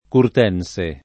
[ kurt $ n S e ]